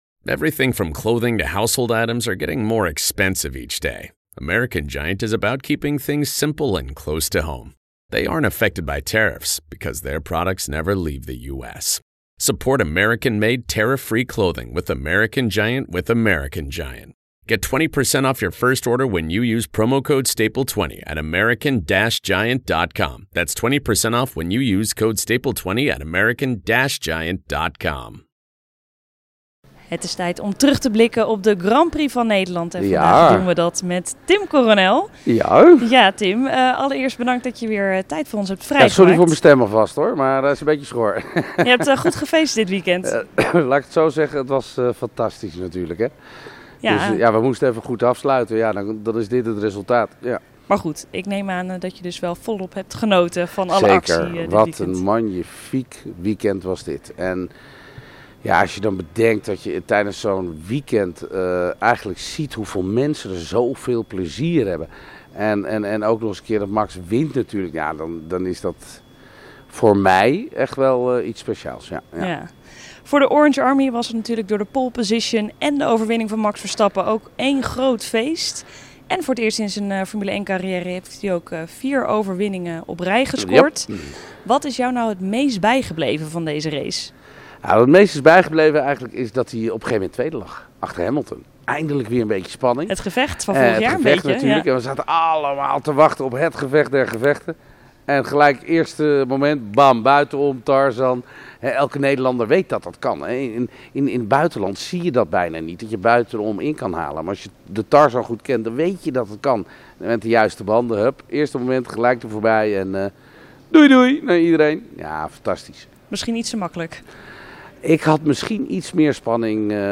Vooral de terugkeer van 'het gevecht van vorig jaar' deed Coronel genieten. Nog schor van het feest na de race, vertelt hij exclusief aan Motorsport.